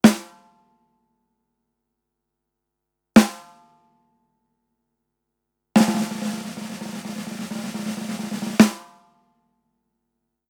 Échantillons sonores Audio Technica AT-4050
Audio Technica AT-4050 - werbel